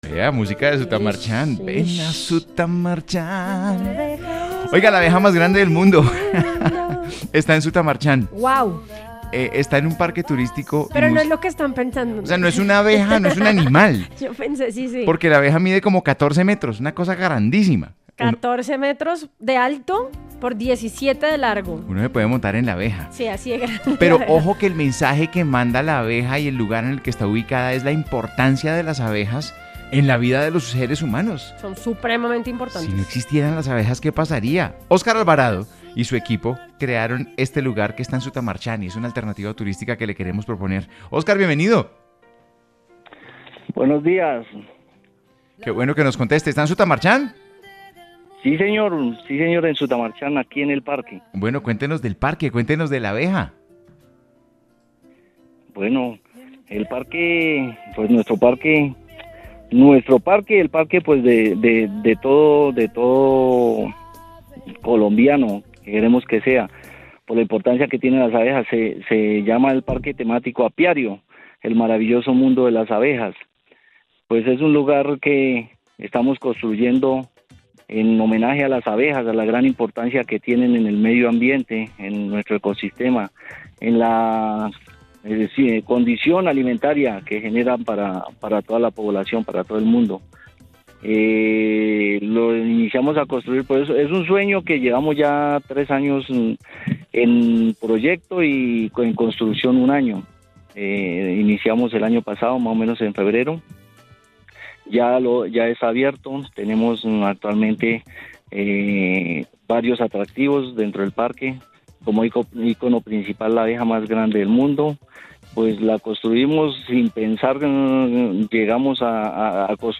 El parque fue destacado en una entrevista en A Vivir Que Son Dos Días, donde sus impulsores explicaron que este espacio busca sensibilizar sobre la importancia de las abejas, su papel en los ecosistemas y su aporte a la seguridad alimentaria, consolidándose como una apuesta por el turismo ambiental y sostenible en la región.